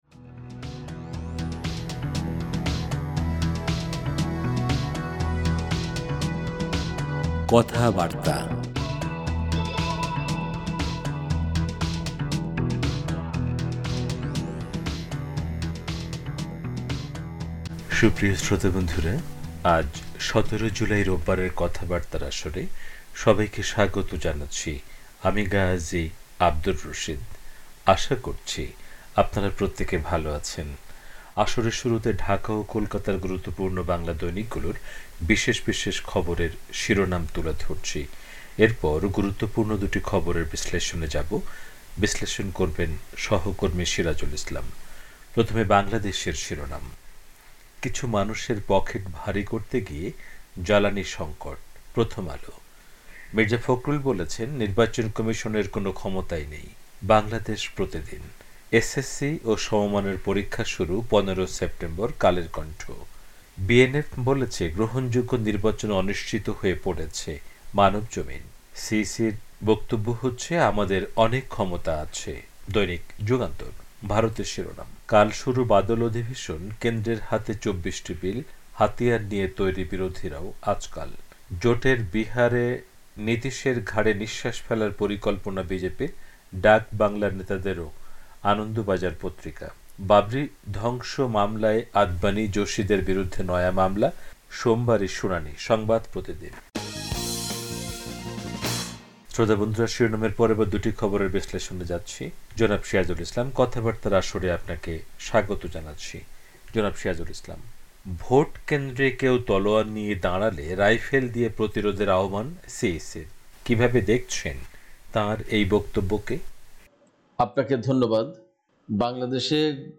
রেডিও অনুষ্ঠানমালা